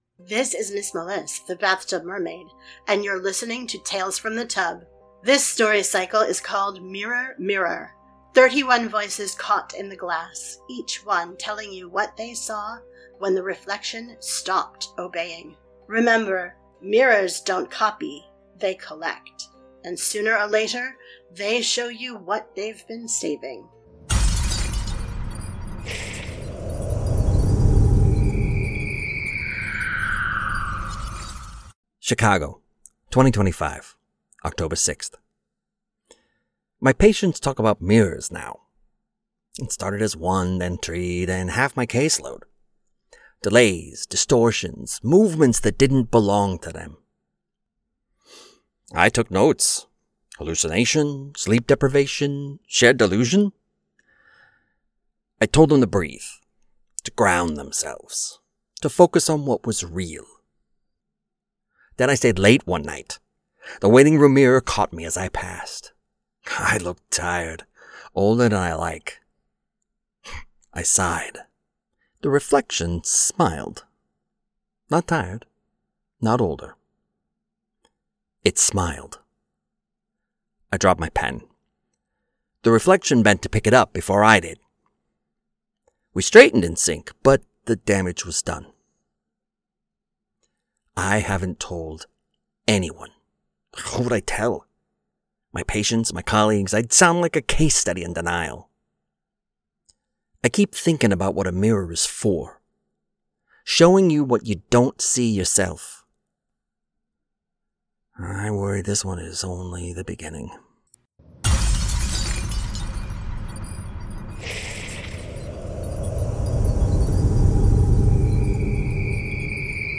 This is Episode Six of Mirror, Mirror, a story cycle of thirty-one monologues on Tales from the Tub.